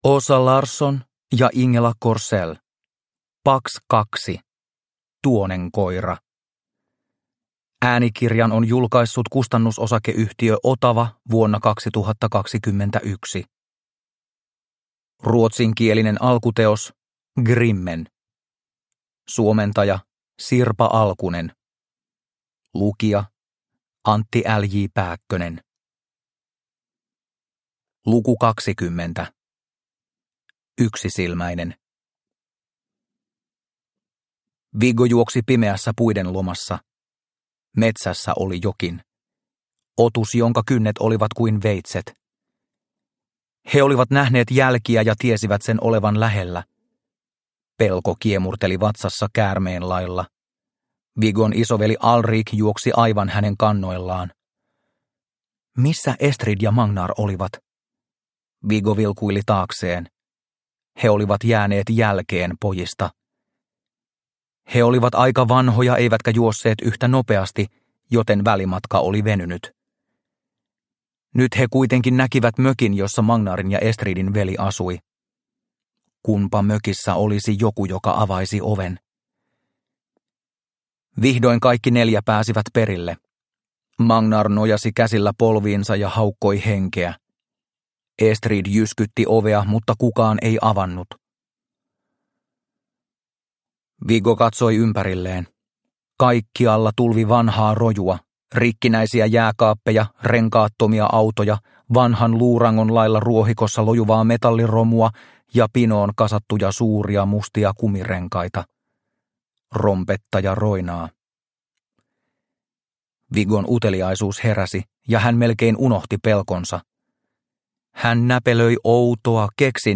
Pax 2 -Tuonenkoira – Ljudbok – Laddas ner